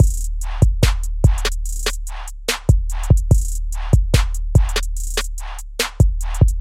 悬念鼓与小鼓
Tag: 160 bpm Drum And Bass Loops Drum Loops 1.01 MB wav Key : Unknown